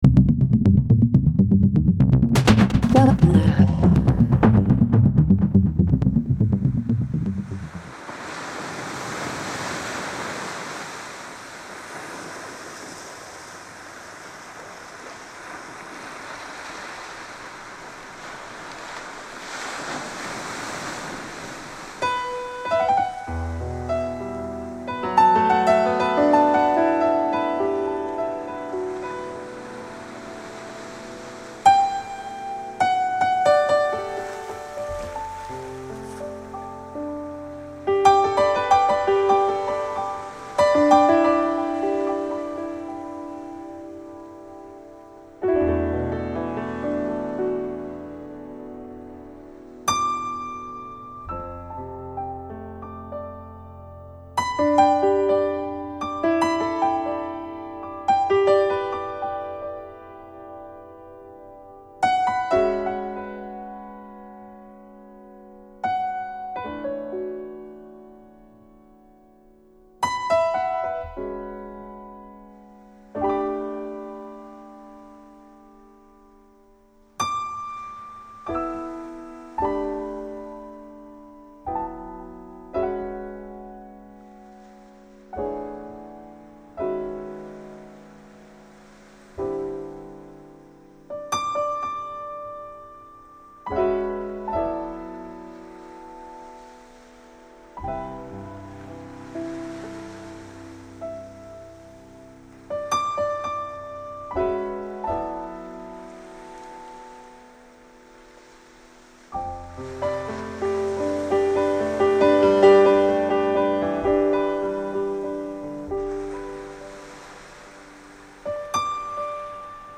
Disco House R&B